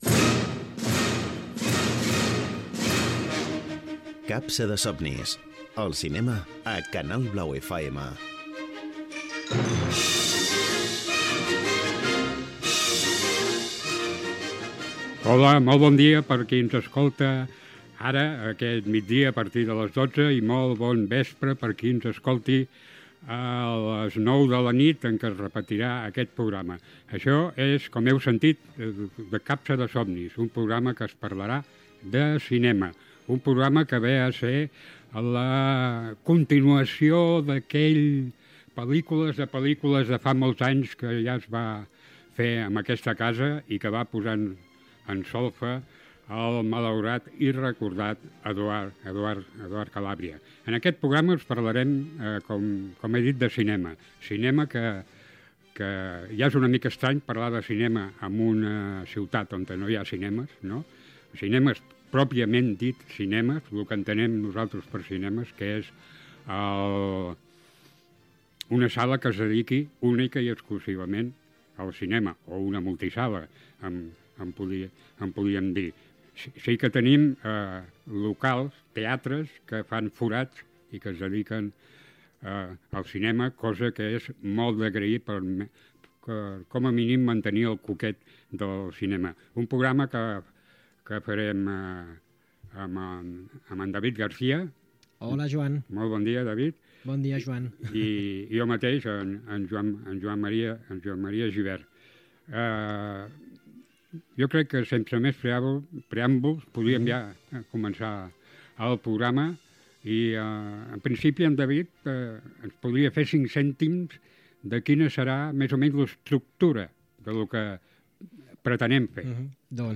Indicatiu del programa, presentació, comentaris sobre les sales de cinema, objectiu i continguts del programa.